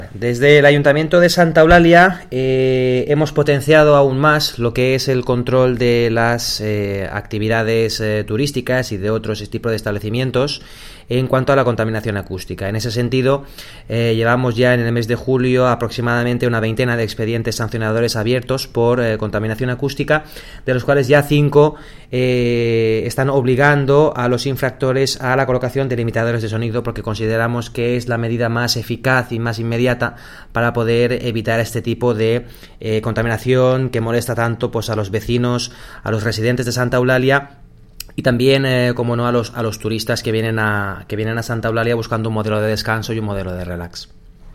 DECLARACIÓN (MP3)
Mariano Juan, concejal de Actividades